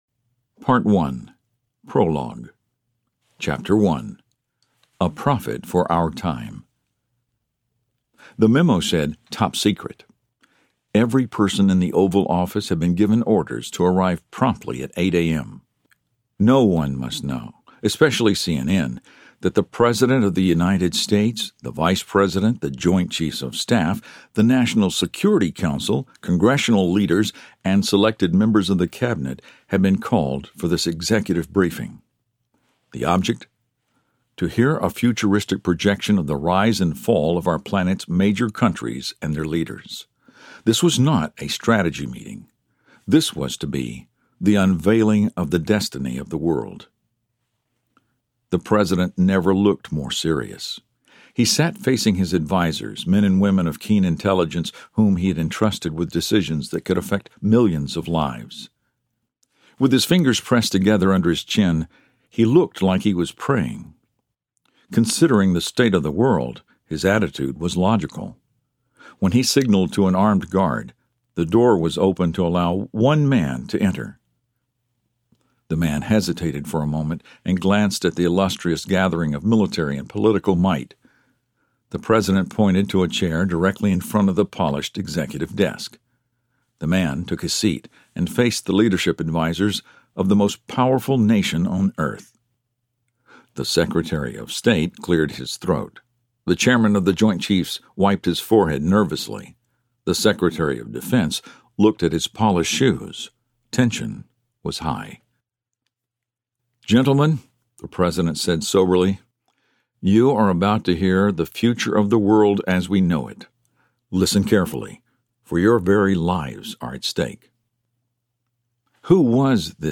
The Handwriting on the Wall Audiobook
Narrator